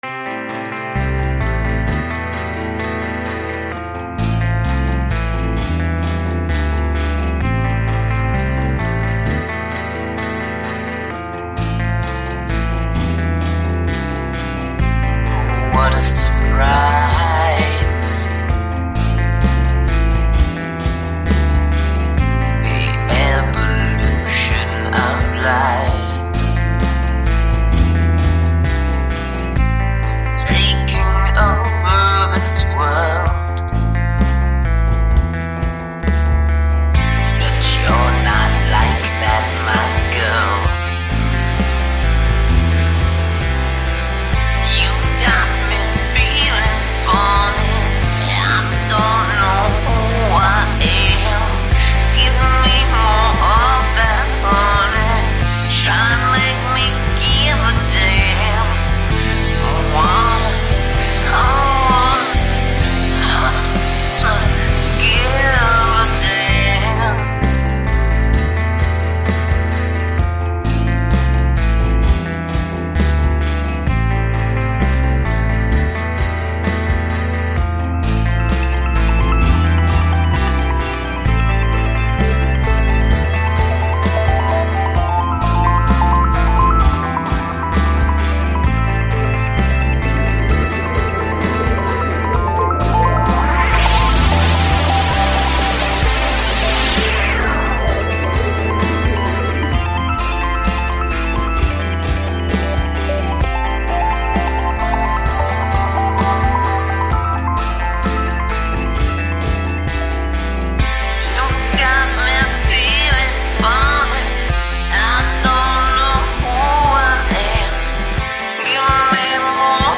That grungy guitar is the stuff of dreams!